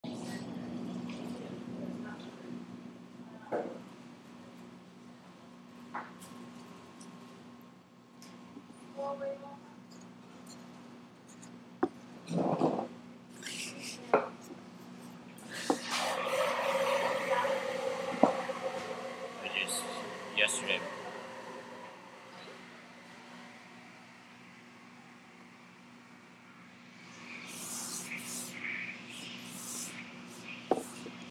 Field Recording 9
FieldRec9 Pura Vida in the library, around 8 AM on Friday, 4/1 You can hear the espresso machine and steam wand as well as people moving chairs, people talking at different distances,…
Pura Vida in the library, around 8 AM on Friday, 4/1